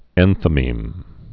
(ĕnthə-mēm)